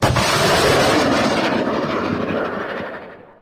Kryzantema ATGM & MSTA-S artillery
I think the ATGM is slightly hollow sounding, and the artillery is too distant sounding, but they could be useful.